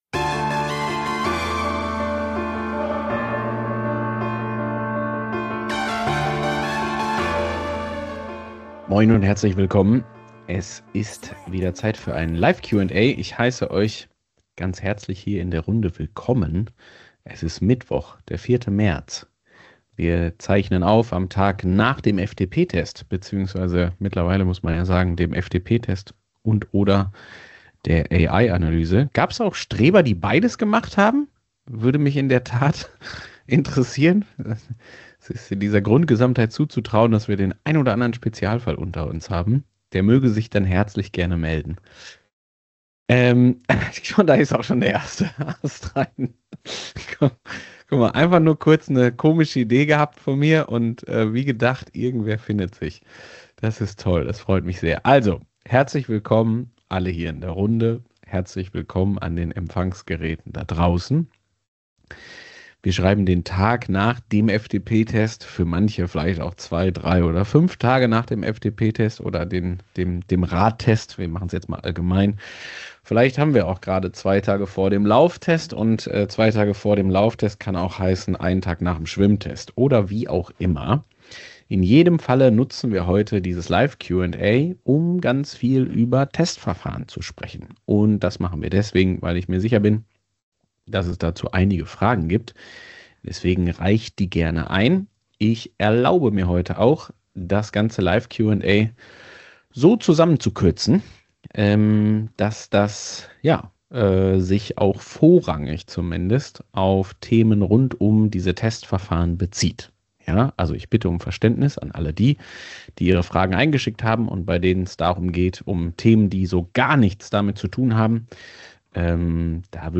Die Live-Session am Tag nach dem FTP- und AI-Test